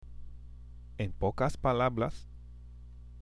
＜発音と日本語＞
（エン　ポカス　パラブラス）